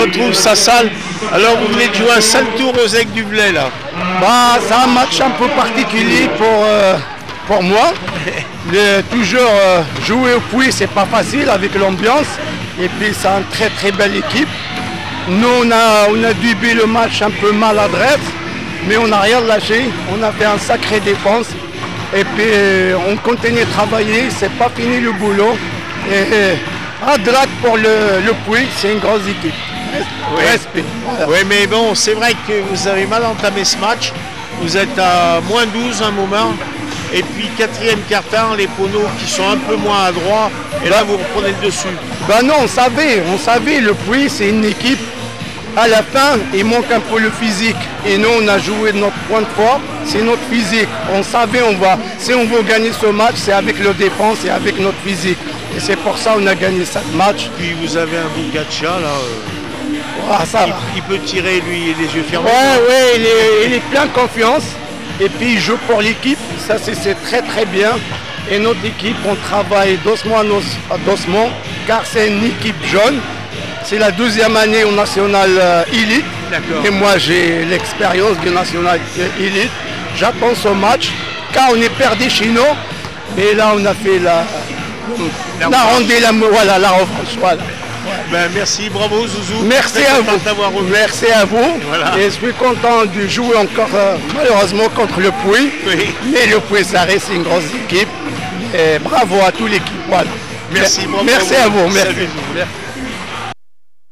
handi basket Elite les aigles du Velay 63-64 élan de chalon réaction après match